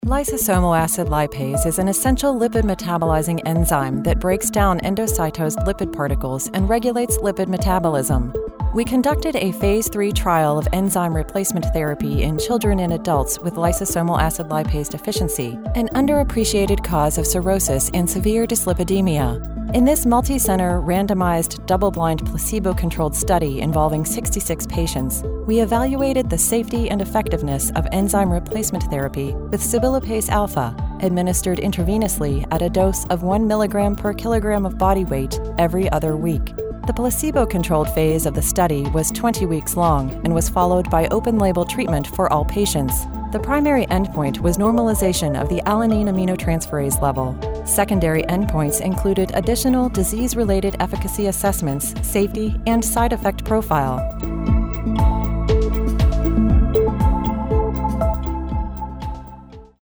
Medical_Lysosomal_technical, well-spoken
Neutral, Mid-Atlantic
Middle Aged